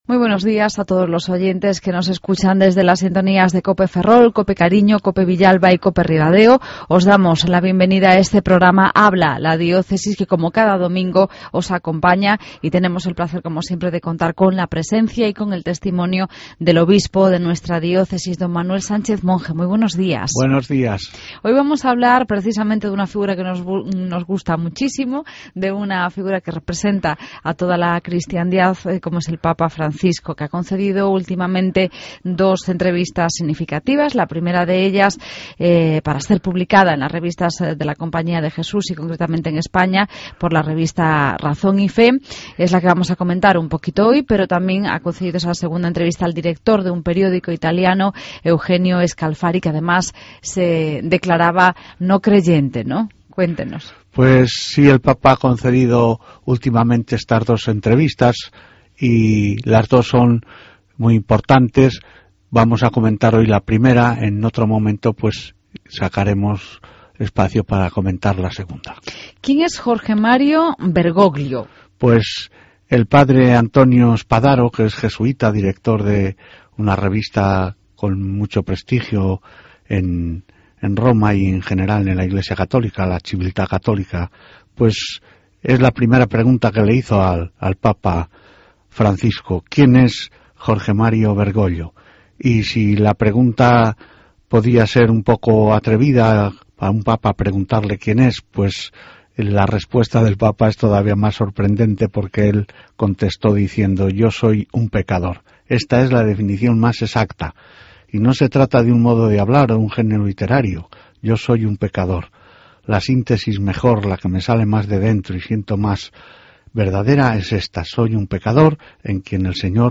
Redacción digital Madrid - Publicado el 07 oct 2013, 11:56 - Actualizado 02 feb 2023, 00:13 1 min lectura Descargar Facebook Twitter Whatsapp Telegram Enviar por email Copiar enlace El obispo de nuestra Diócesis, Don Manuel Sánchez Monge, nos habla de las últimas entrevistas del Papa Francisco.